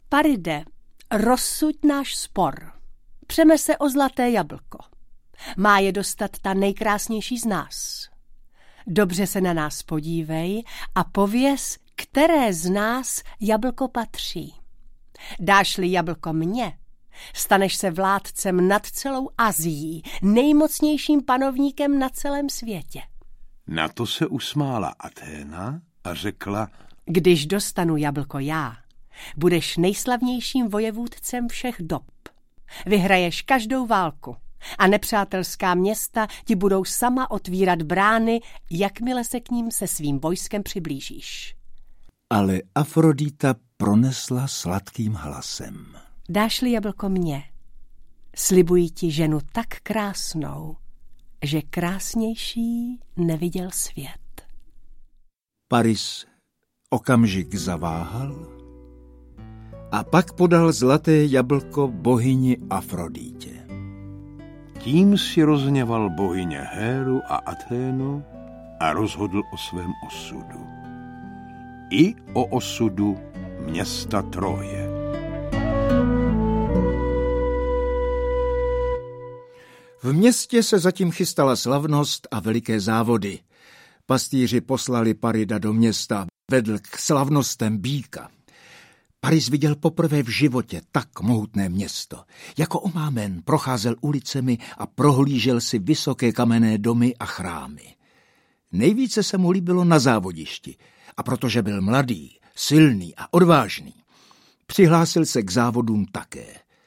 Ukázka z knihy
• InterpretTaťjana Medvecká, Petr Pelzer, František Němec